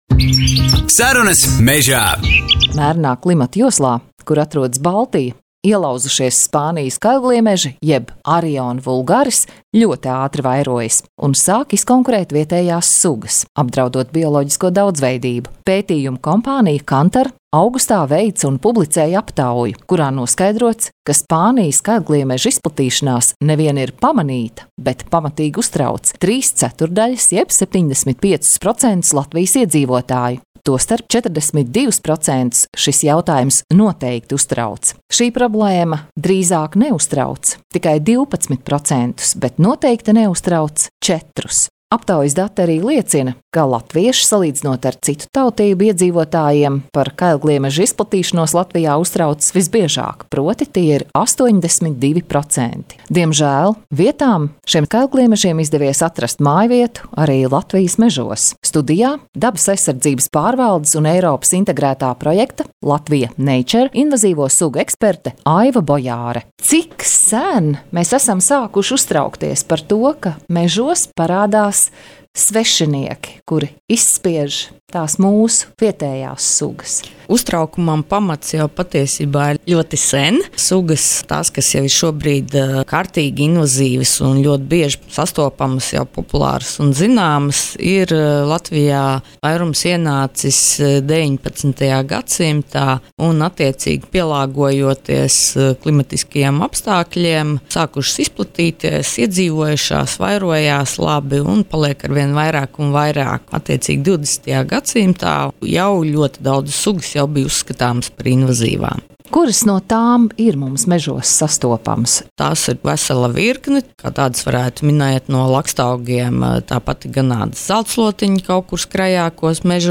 Intervijas un fakti- tas viss – „Sarunās mežā”.